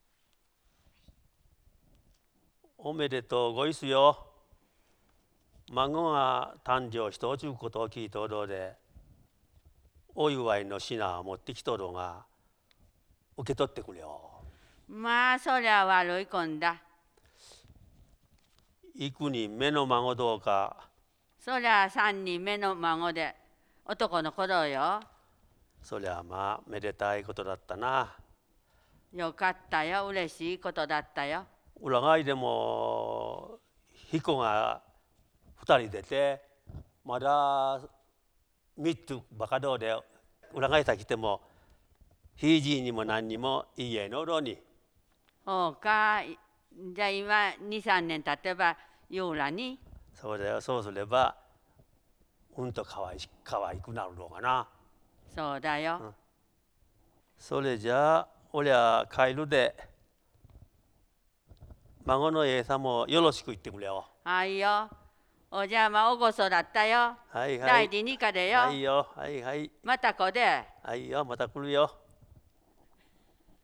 会話（ロールプレイ） ─奈良田─
[4] みっつばかどーで：「みっつ」と「ばか」の間に休止がある。